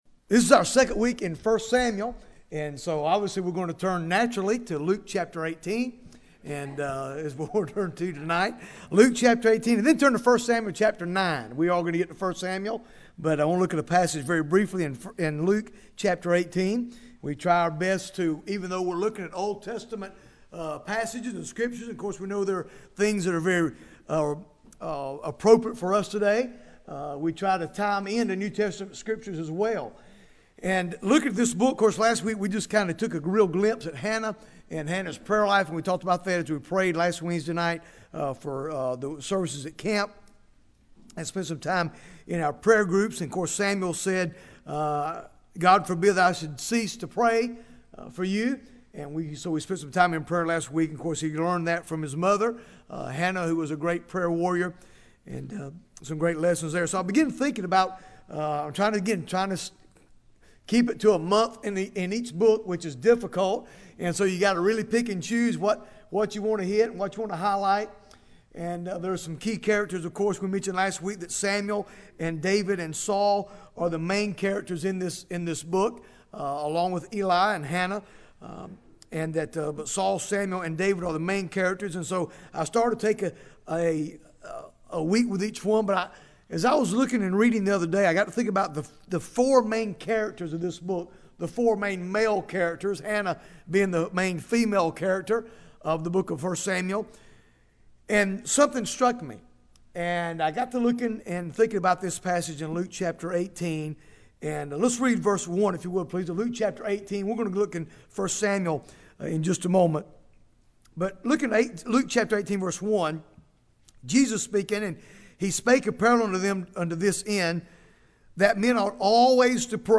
Bible Text: Luke 18 | Preacher